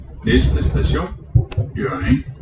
Højttalerudkald - "Næste station.."